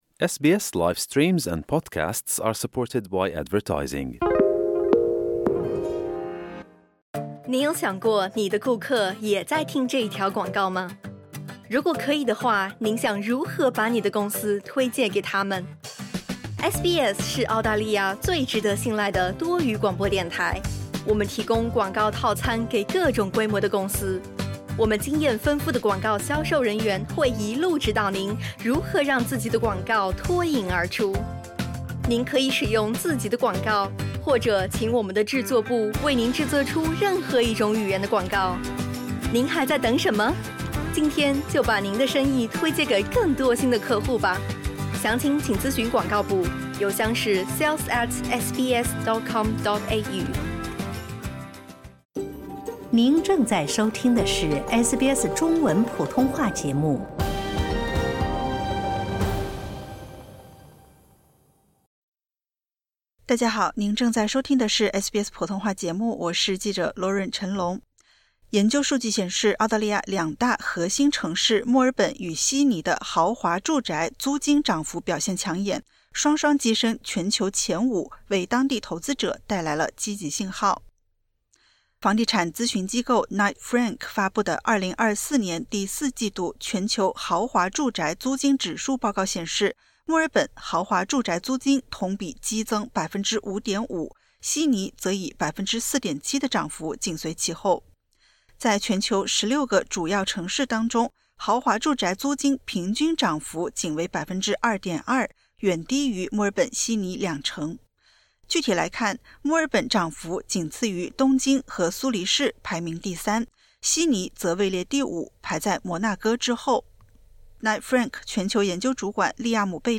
研究数据显示，澳大利亚两大核心城市墨尔本与悉尼的豪华住宅租金涨幅表现抢眼，双双跻身全球前五，为当地投资者带来积极信号。点击 ▶ 收听完整报道。